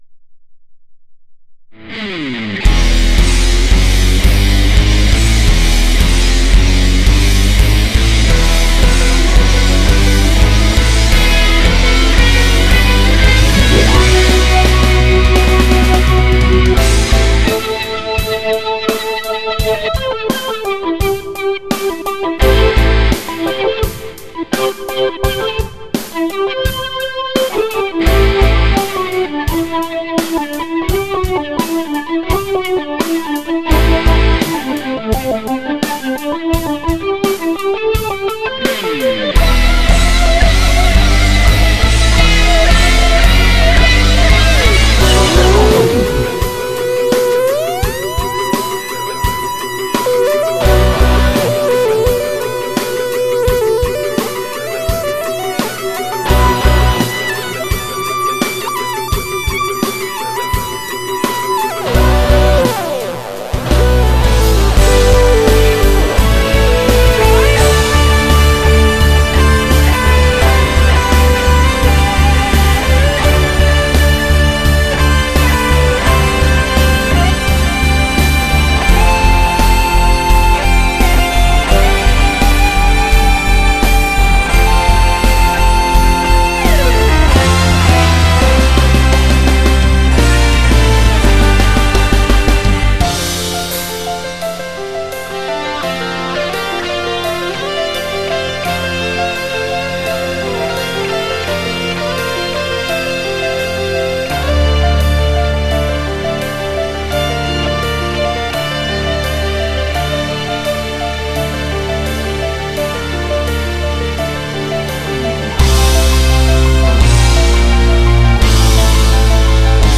lab Korg i4s
Aria-2010-Korg-i4s-Sound.mp3